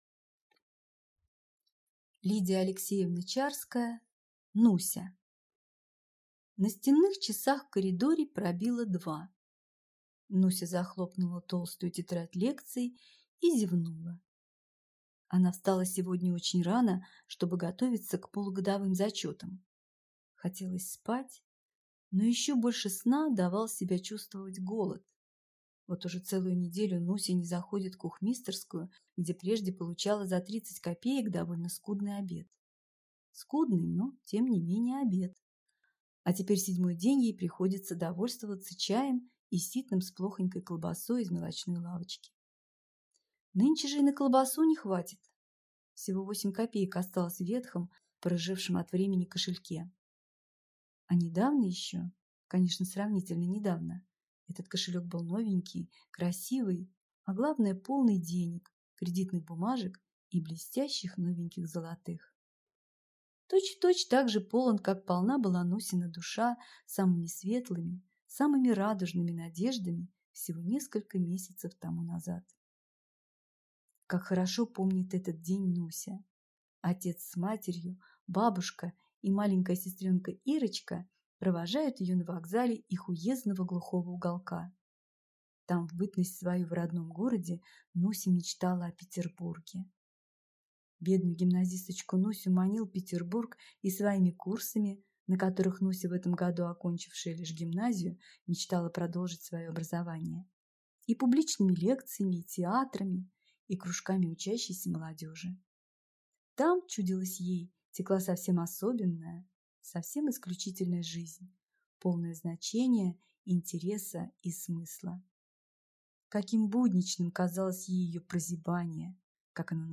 Аудиокнига Нуся | Библиотека аудиокниг